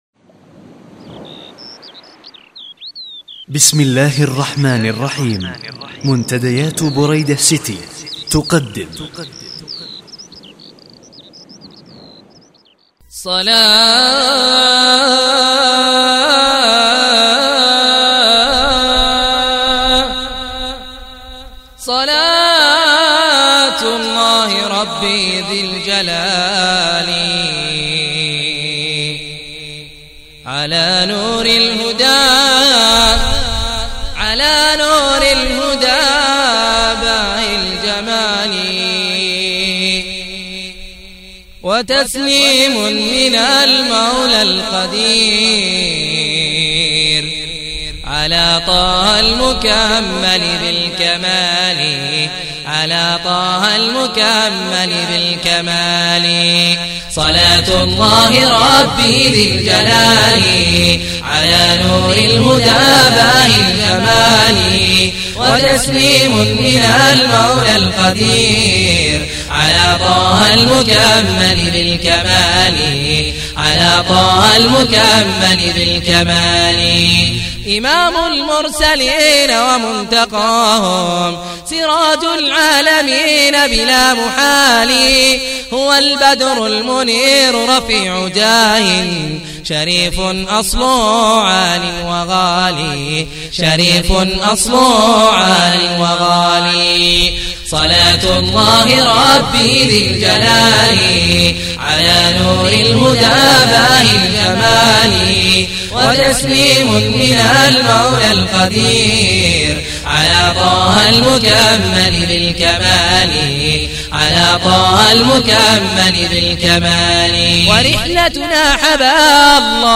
صـوتــيـات و مـرئـيـات دروس ، محاضرات ، خطب ، أناشيد
العمل بحد ذاته جميل وتجربة رائعة وصوت شجي جميل جدا
النفس اي طبقات الصوت غير مرتبة والكورال فيه بعض الغبش كاقتراب المايك إلى الفم مباشرة وتصدر منها هواء النفس